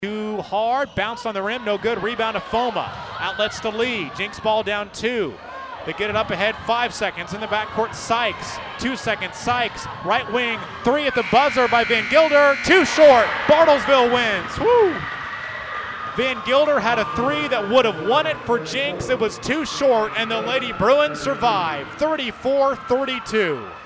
Here is how the final call sounded Thursday night on KWON.